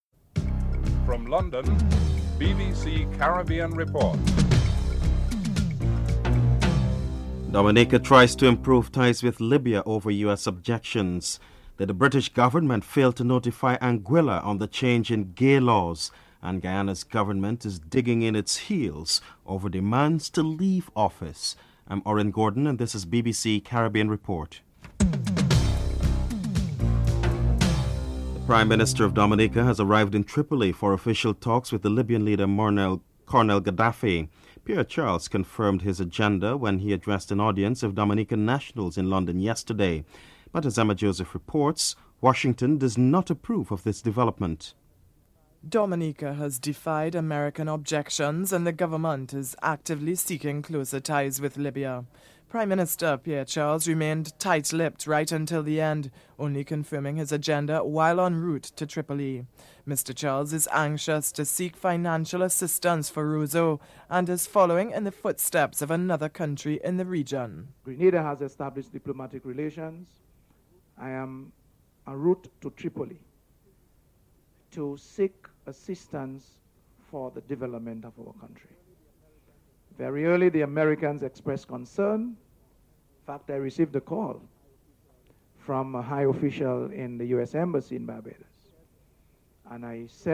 1. Headlines (00:00-00:27)
Commonwealth Secretary General Don McKinnon and Prime Minister Owen Arthur are interviewed.